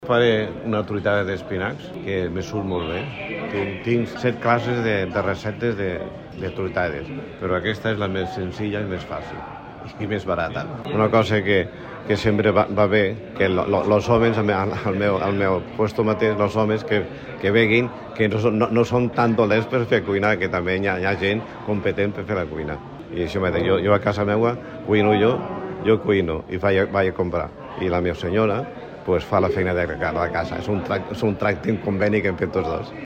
cuiner-senyor.mp3